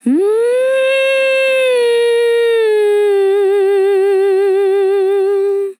TEN VOCAL FILL 21 Sample
Categories: Vocals Tags: dry, english, female, fill, sample, TEN VOCAL FILL, Tension